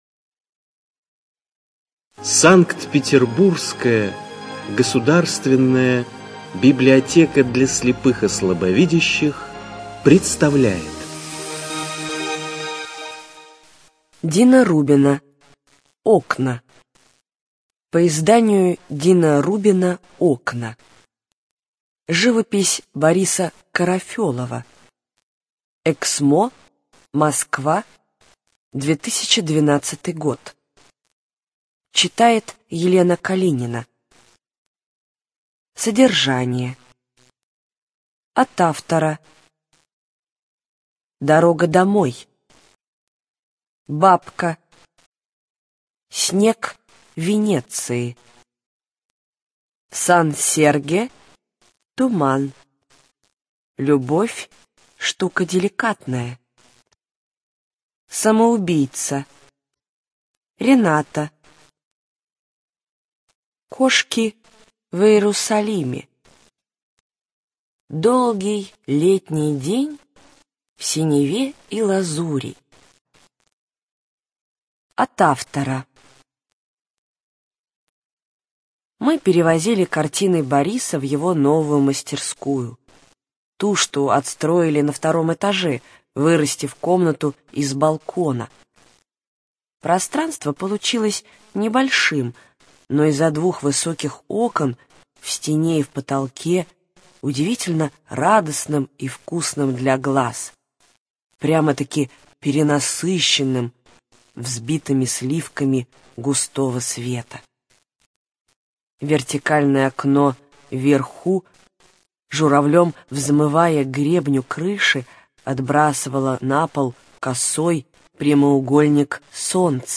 Студия звукозаписиСанкт-Петербургская государственная библиотека для слепых и слабовидящих